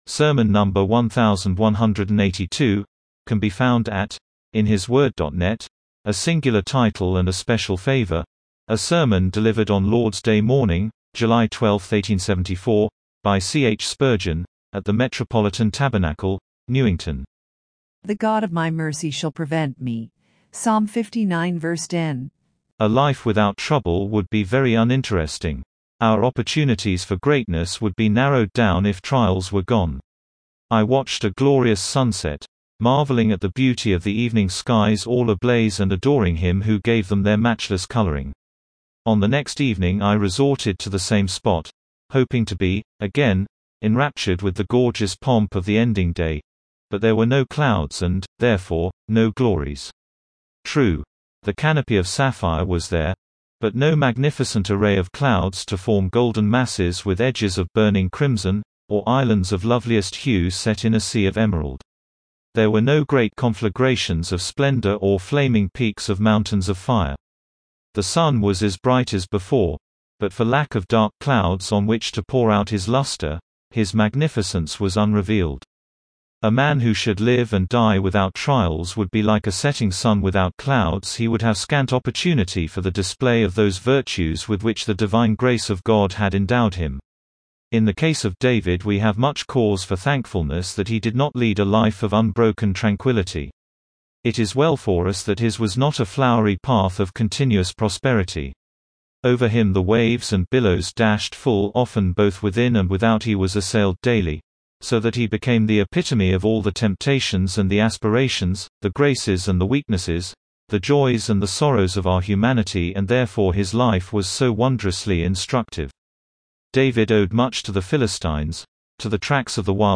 Sermon #1,182, A SINGULAR TITLE AND A SPECIAL FAVOR